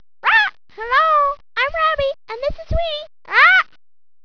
Robby is a Hyacinth Macaw, one of the biggest birds in the world.
blue hyacinth macaw
If you want to hear Robby talk, click on him, but you have to turn off the music at the bottom of the page first :) hehhehehehehe